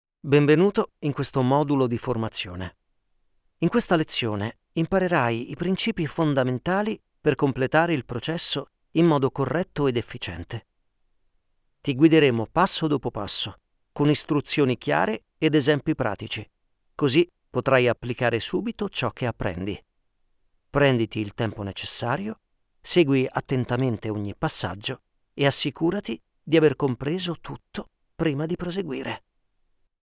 Muestras de voz nativa
E-learning
Adulto joven